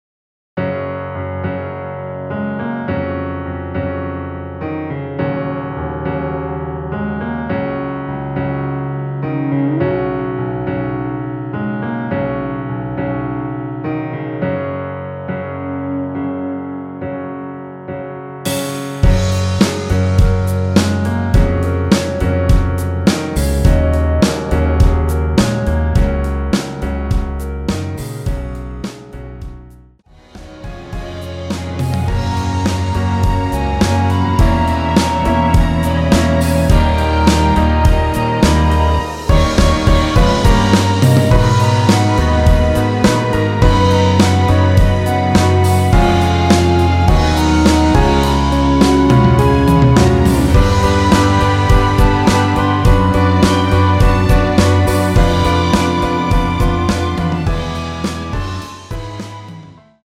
원키에서(-2)내린 멜로디 포함된 MR입니다.(미리듣기 확인)
F#
앞부분30초, 뒷부분30초씩 편집해서 올려 드리고 있습니다.
중간에 음이 끈어지고 다시 나오는 이유는